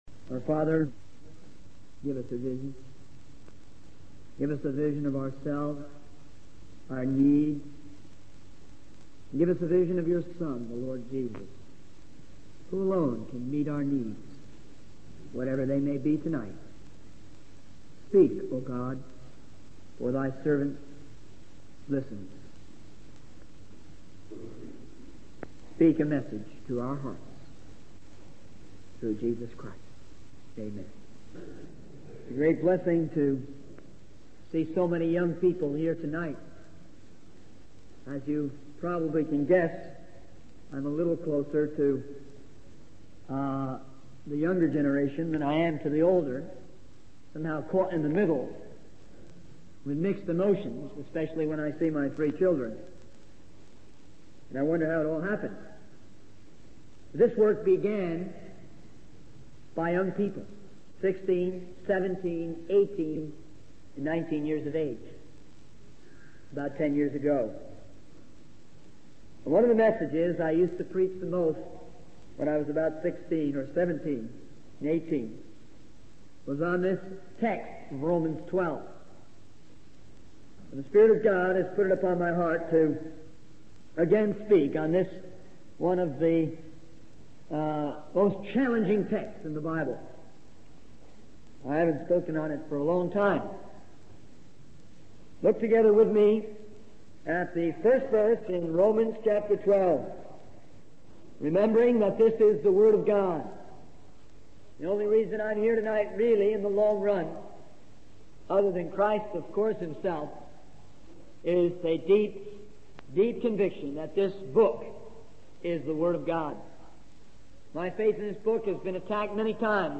In this sermon, the preacher describes a powerful experience where young people from the streets came into the church and gave their lives to Jesus Christ. The preacher emphasizes the importance of obedience and trust in God, rather than seeking special experiences. He also highlights the urgency of presenting our bodies as living sacrifices because Jesus Christ is coming soon.